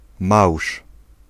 Ääntäminen
Ääntäminen Tuntematon aksentti: IPA: [mawʂ] Haettu sana löytyi näillä lähdekielillä: puola Käännös Ääninäyte Substantiivit 1. mussel RP US Suku: m .